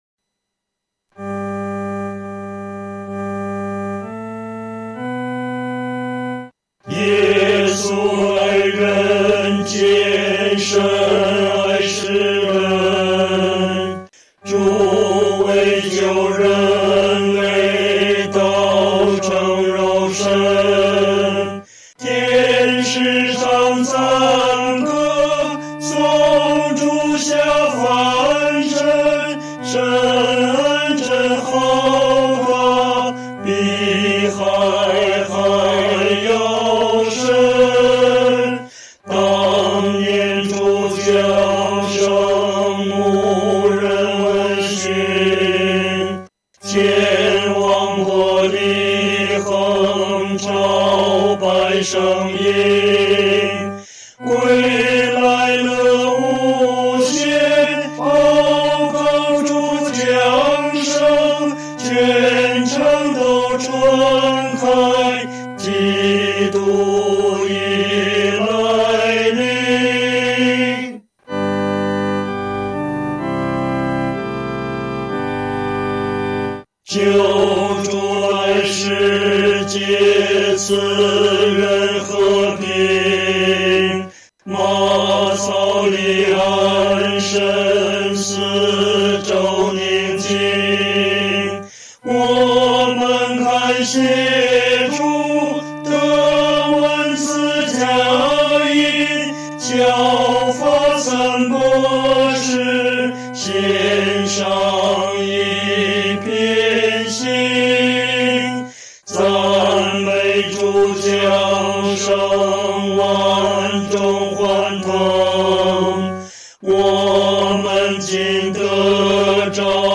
男高
本首圣诗由网上圣诗班（环球）录制
这首诗歌曲调是按歌词的内容、意境逐渐铺开进行的。前两句平稳幽静，悠然从容。第3句引吭高歌，似与天使一同唱和赞主降生。